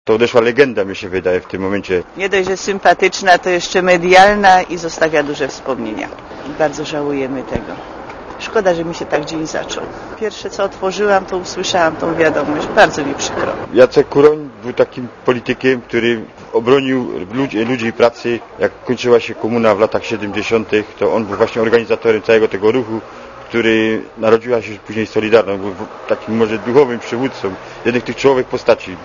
Mieszkańcy Katowic